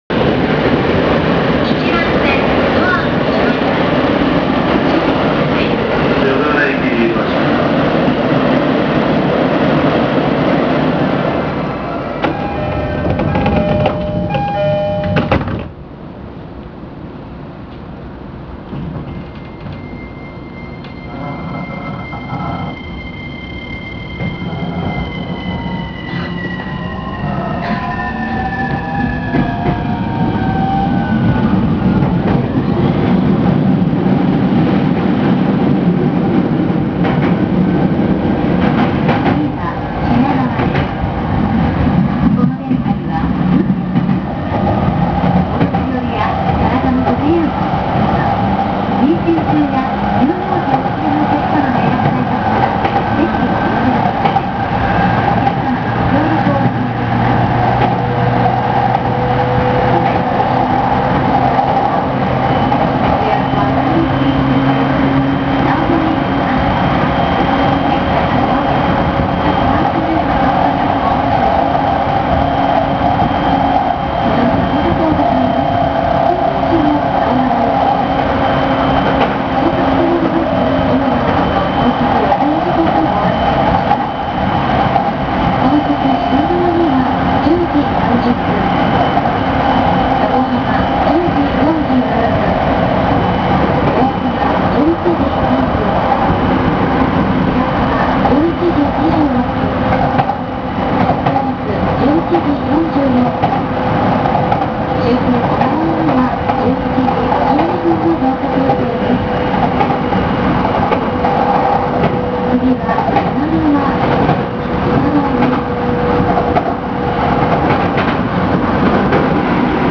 ・近郊型日立IGBT走行音
【東海道線】新橋〜品川（5分18秒：1.68MB）
近郊型では基本的に、900番台の千葉側４両のモーターが生かされることになったのですが、音の響き方が随分と変わってしまいました。他の電車でまったく聞いたことのないこの特徴のある音。加速すると音が下がり、減速すると音が上がります。